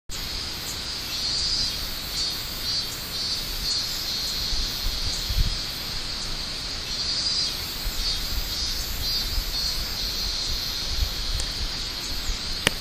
それにミンミンゼミがとても賑やかでした。